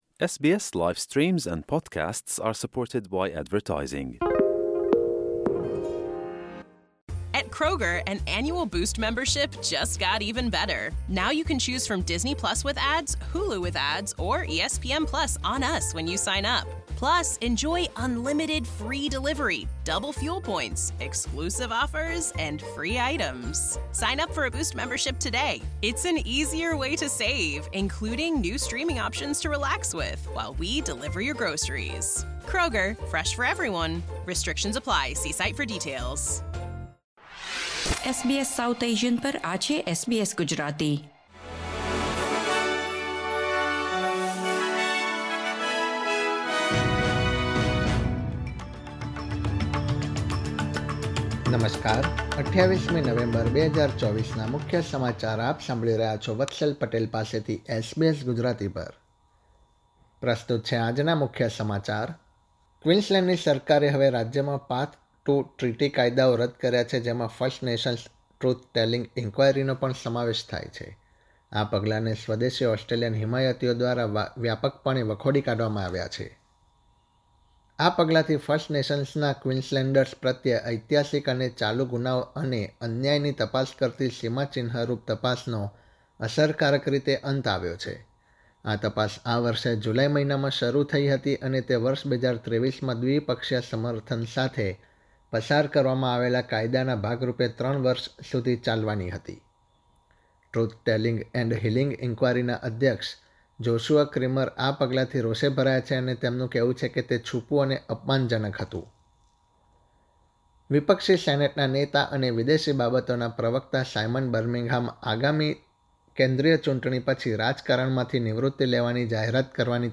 ૨૮ નવેમ્બર ૨୦૨૪ના મુખ્ય સમાચાર